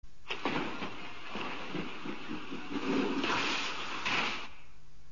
Звуки спорта (500)